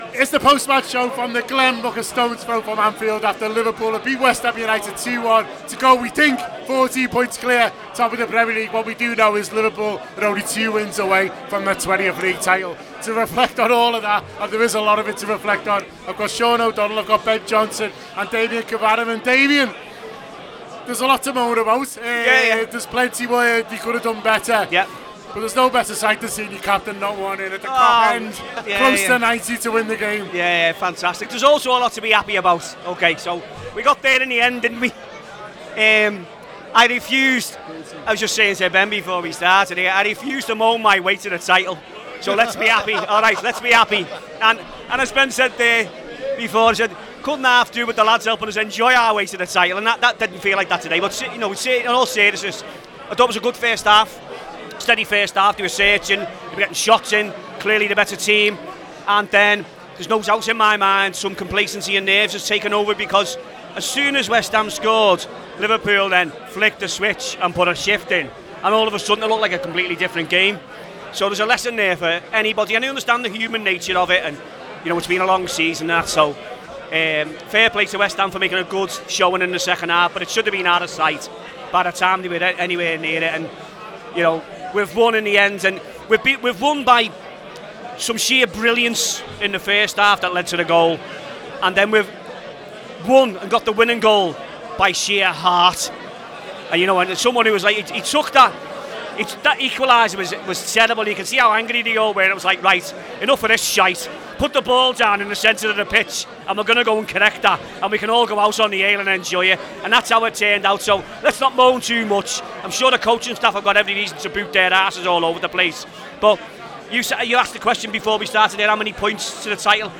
Liverpool 2 West Ham 1: Post-Match Show